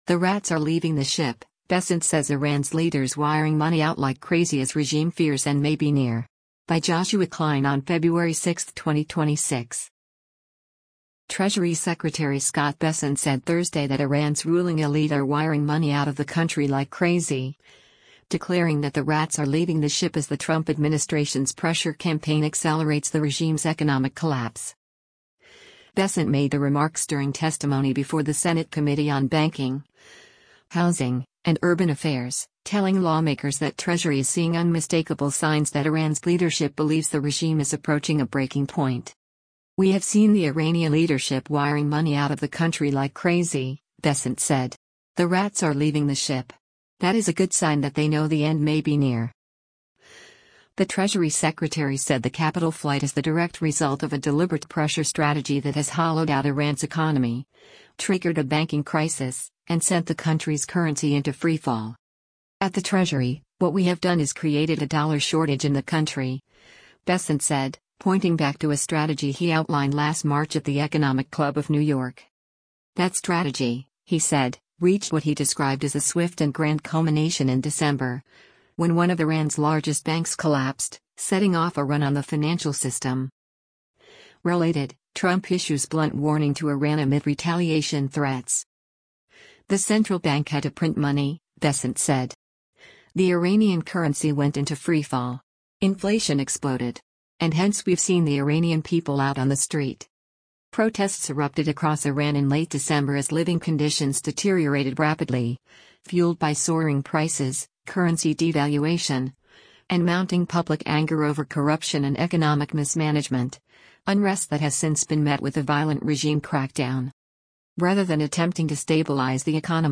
Bessent made the remarks during testimony before the Senate Committee on Banking, Housing, and Urban Affairs, telling lawmakers that Treasury is seeing unmistakable signs that Iran’s leadership believes the regime is approaching a breaking point.